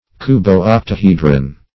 Search Result for " cubo-octahedron" : The Collaborative International Dictionary of English v.0.48: Cubo-octahedron \Cu`bo-oc`ta*he"dron\ (-dr?n), n. (Crystallog.) A combination of a cube and octahedron, esp. one in which the octahedral faces meet at the middle of the cubic edges.